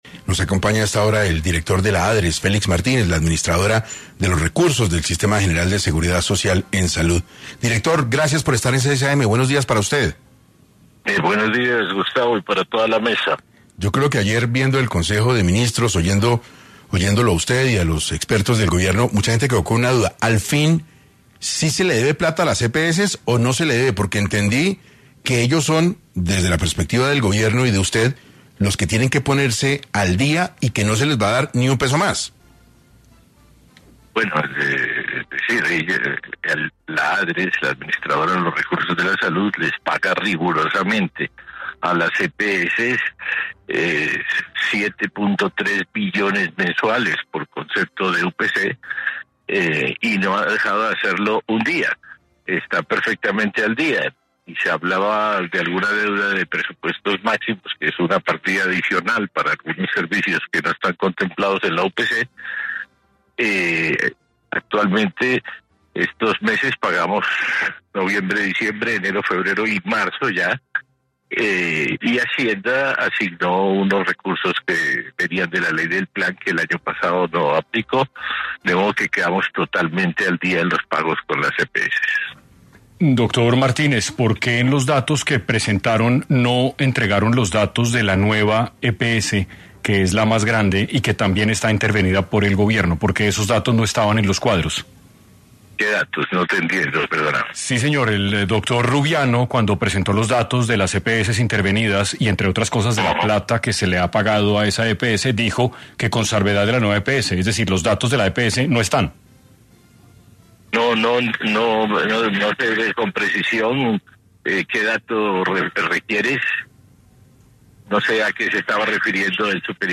En 6AM habló Félix Martínez, director de la Adres sobre las millonarias deudas que presentan las EPS y la crisis de medicamentos que vive el país.